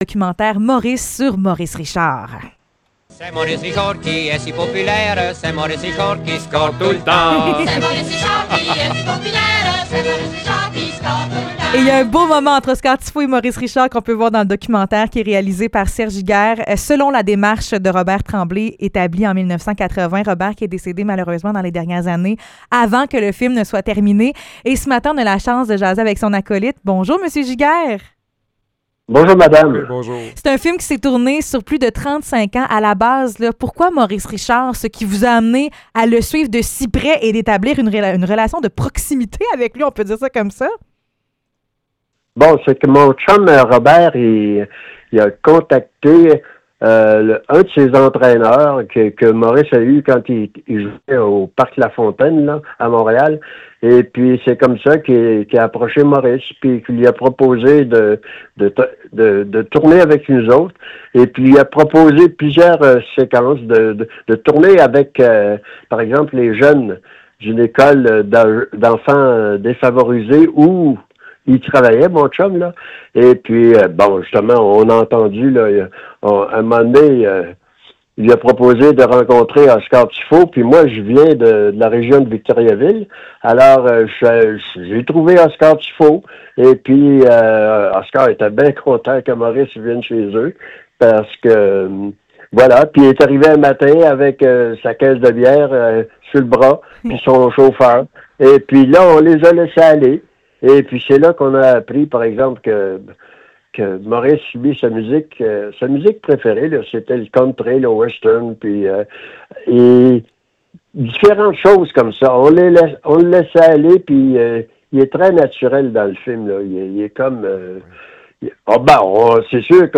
Entrevue pour le film documentaire sur Maurice Richard